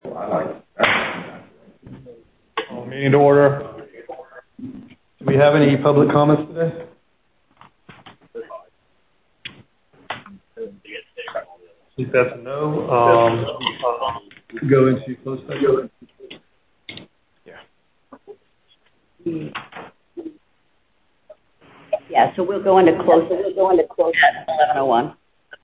Board Meeting Minutes
Teleconference Audio 1